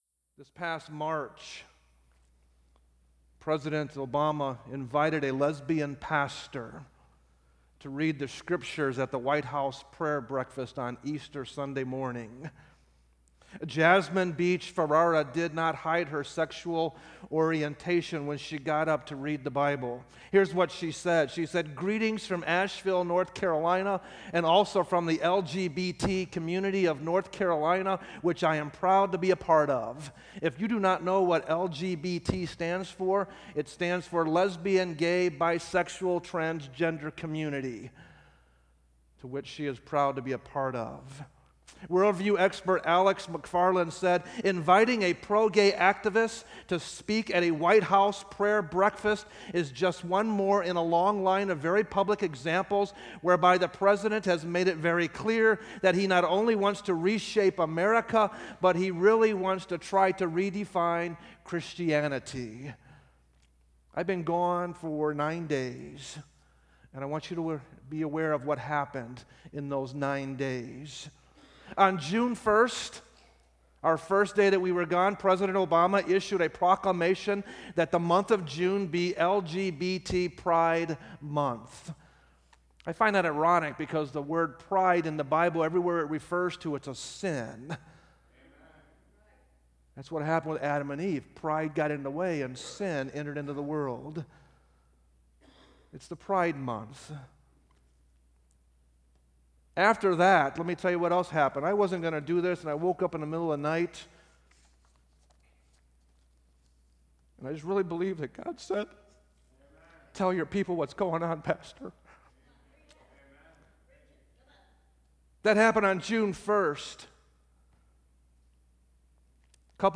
sermons preached at Grace Baptist Church in Portage, IN